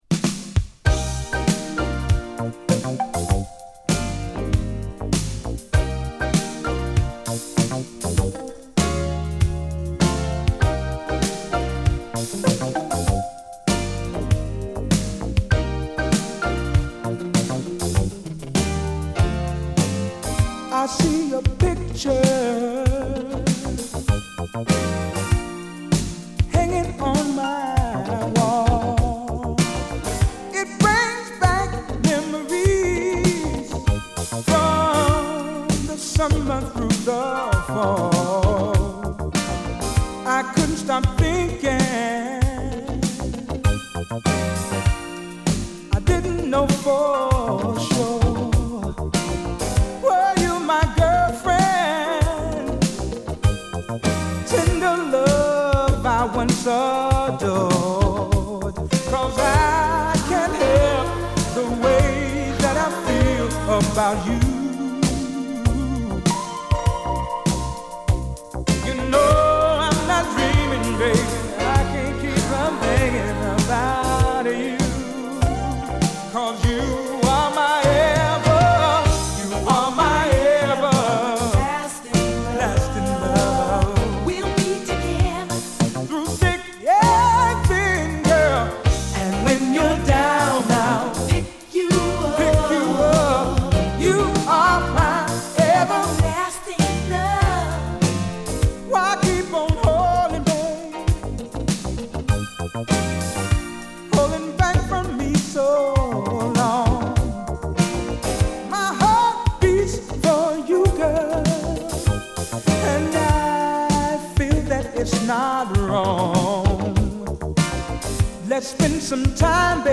アーバンなミッドテンポトラックに女性コーラスを配して、こみあげるヴォーカルを披露する都会的80sソウル！